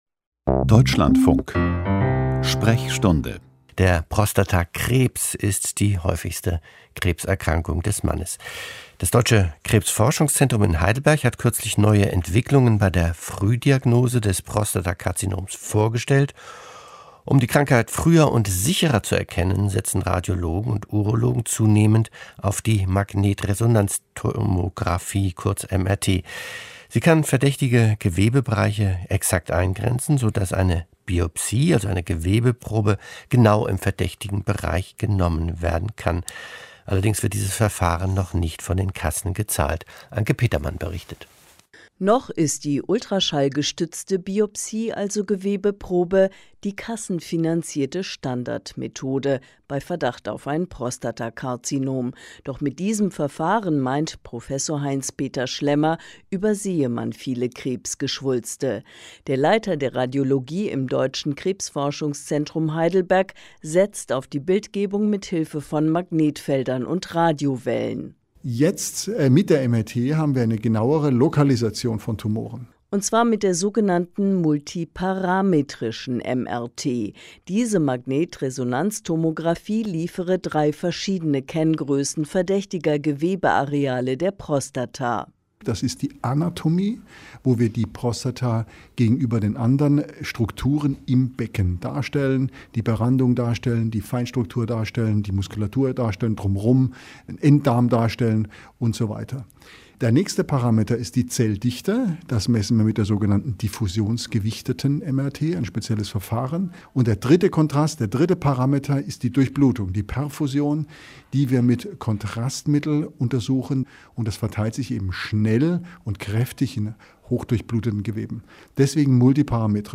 Ein Experte gibt Auskunft über den Erkenntnisstand seines medizinischen Fachgebietes und beantwortet anschließend Hörerfragen; die Sendung wird ergänzt durch einen aktuellen Info-Block.